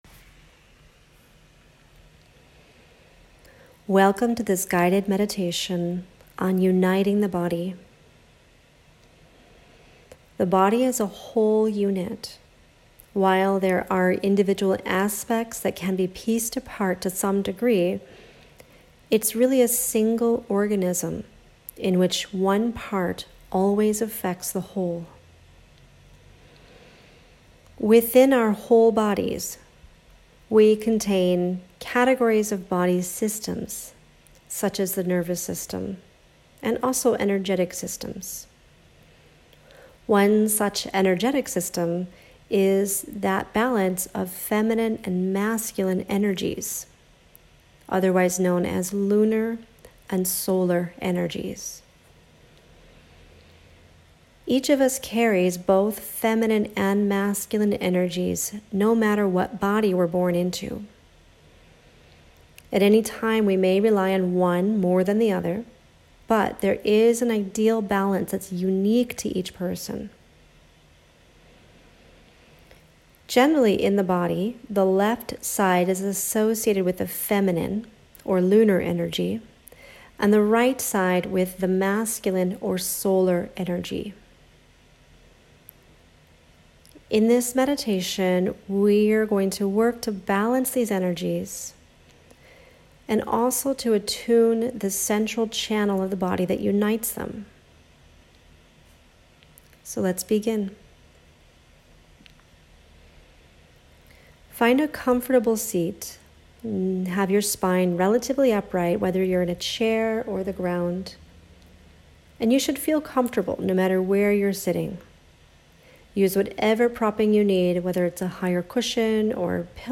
August guided meditation: Balancing the Feminine and Masculine Energies (10 minutes) - BODYSTORY